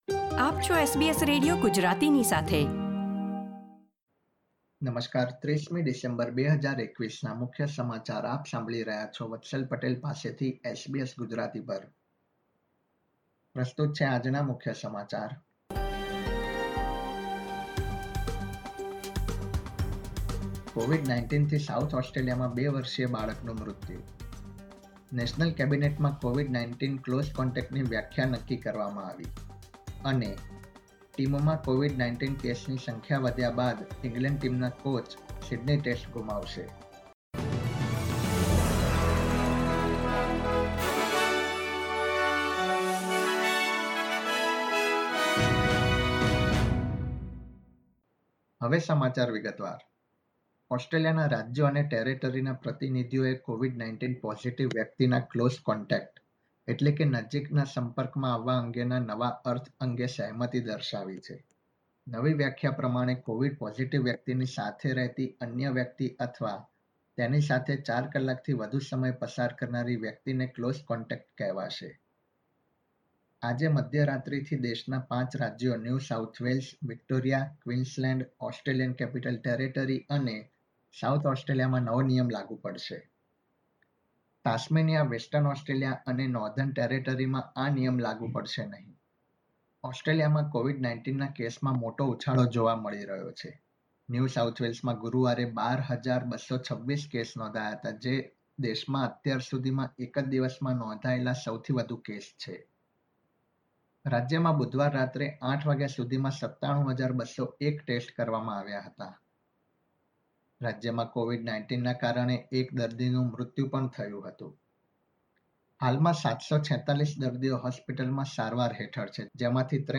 SBS Gujarati News Bulletin 30 December 2021